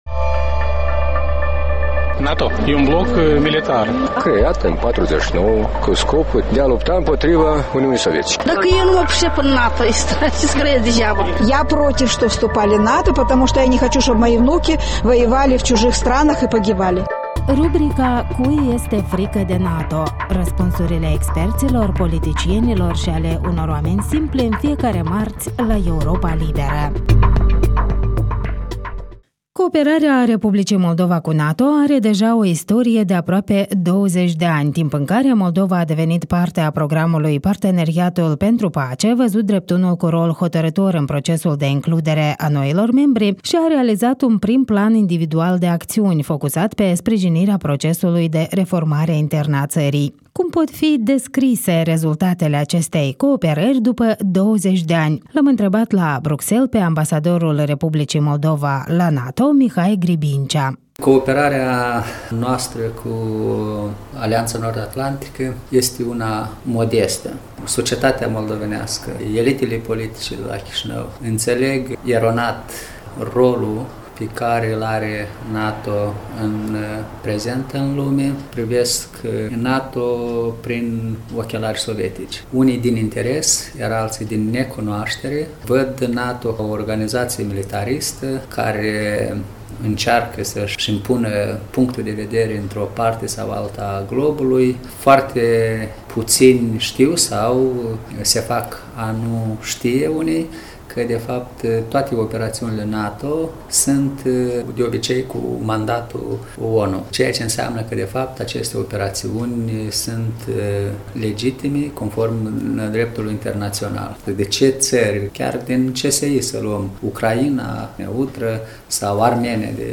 Un interviu cu Mihai Gribincea, ambasadorul R. Moldova la NATO